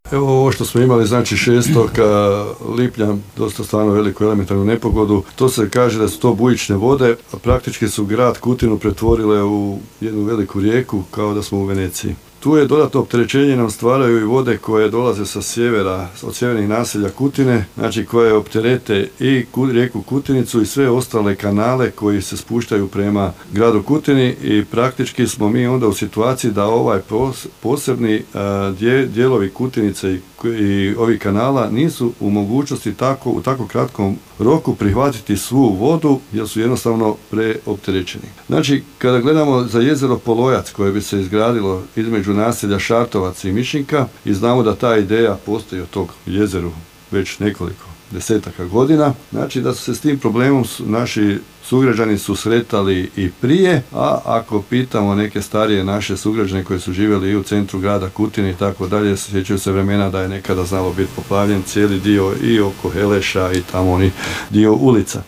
Gradonačelnik Kutine Zlatko Babić osvrnuo se na nedavnu elementarnu nepogodu te na akumulaciju Polojac koja bi pomogla pri rješavanju problema plavljenja grada